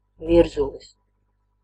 Ääntäminen
Ääntäminen France: IPA: [pis.tõ] Tuntematon aksentti: IPA: /pis.tɔ̃/ Haettu sana löytyi näillä lähdekielillä: ranska Käännös Ääninäyte Substantiivit 1. virzulis {m} Suku: m .